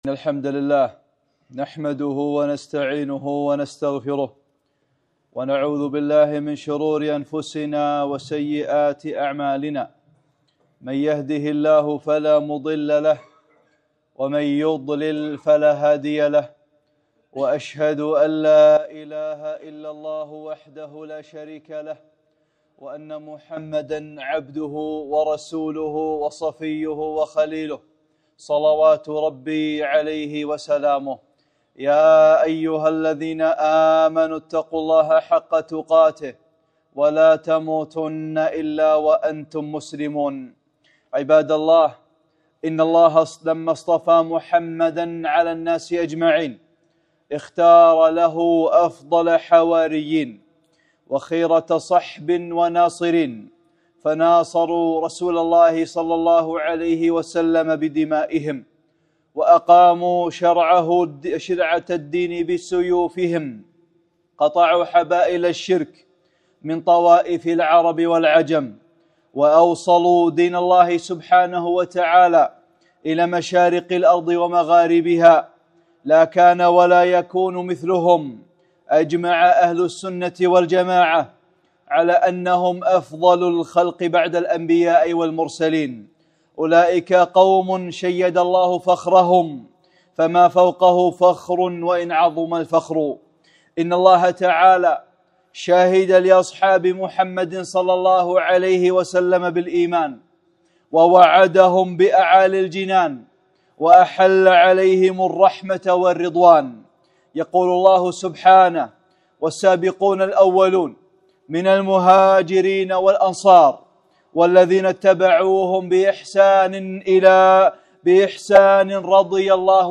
خطبة - خير القرون